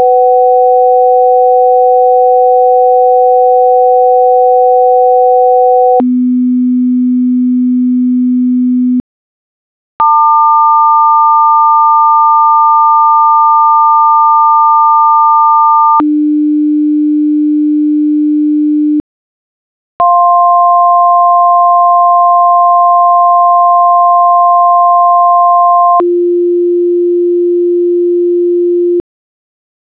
500 Hz/ 750 Hz together, followed by the 250 Hz fundamental
900 Hz/ 1200 Hz together, followed by the 300 Hz fundamental
700 Hz/ 1050 Hz together, followed by the 350 Hz fundamental
periodicitypitch.wav